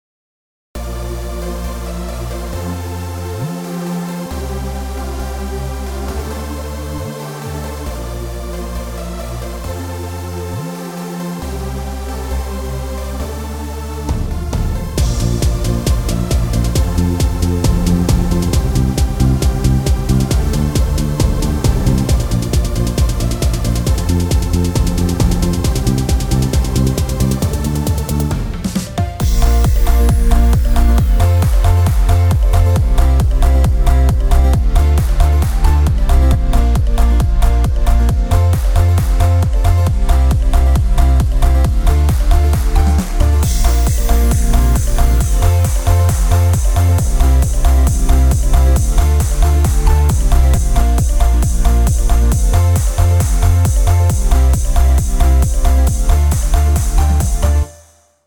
טראק אורגן קורג